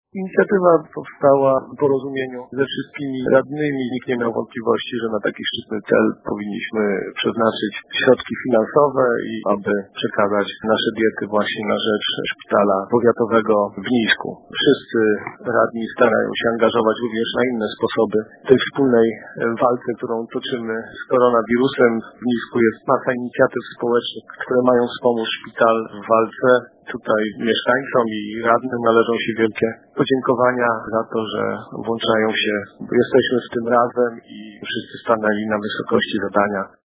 Mówi przewpodniczący Rady Miejskiej w Nisku Marcin Fołta.